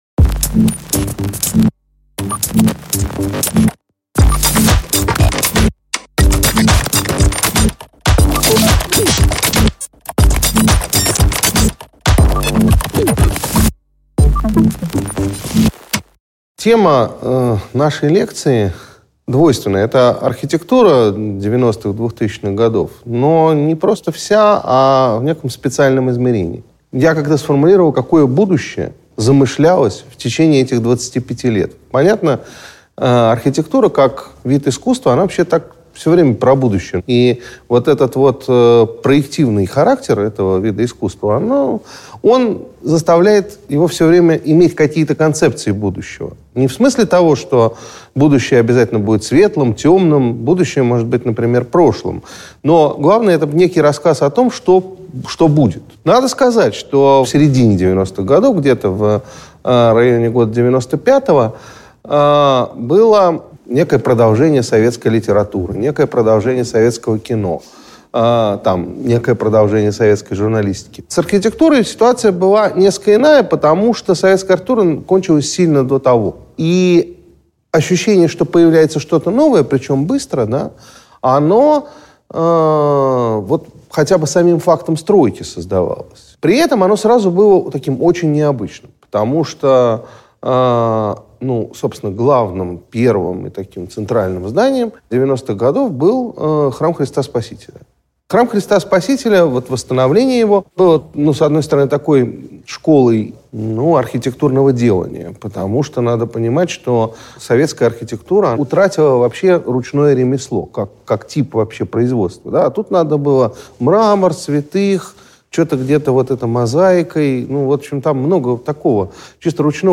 Аудиокнига Вперед в прошлое. Григорий Ревзин – об образах будущего в постсоветской архитектуре | Библиотека аудиокниг